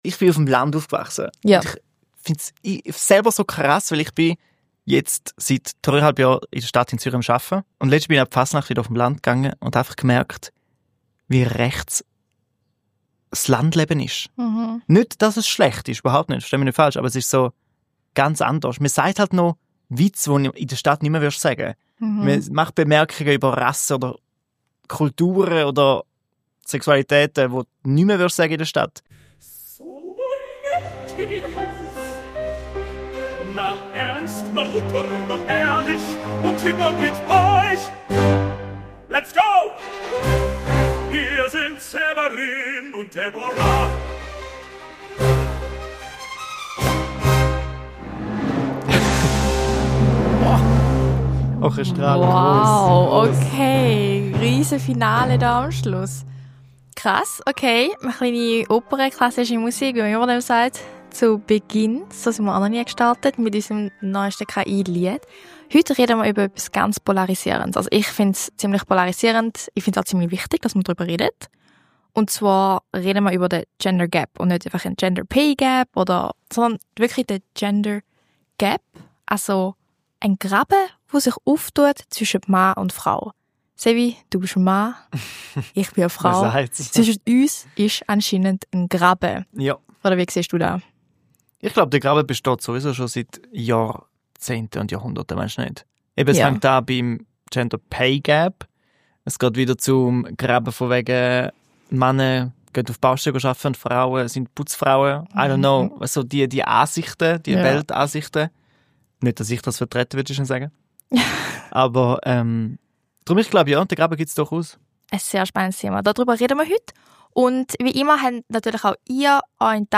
Ausserdem erzählen Mitglieder Schweizer Jungparteien, wieso sie denken, dass es einen Gender Gap gibt und was man dagegen tun könnte.